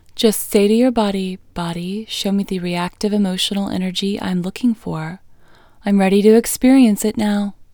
LOCATE Short OUT English Female 9